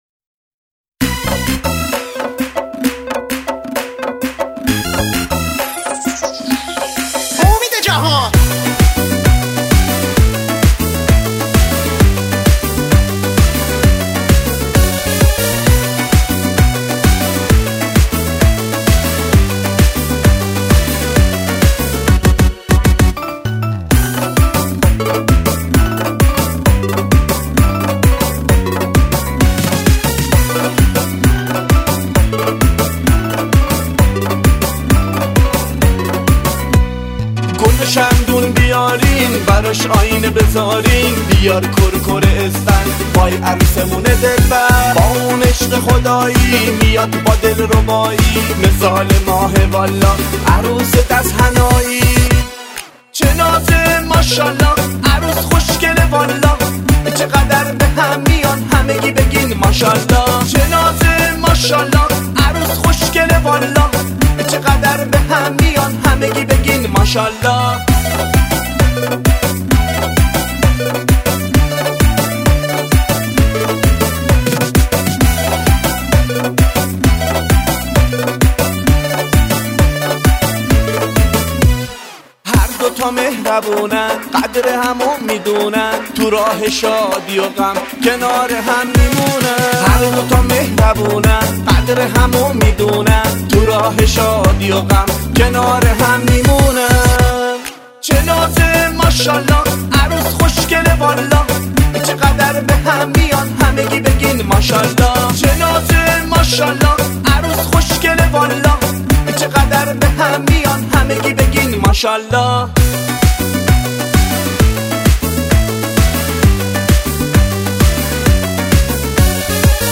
با ریمیکس تند است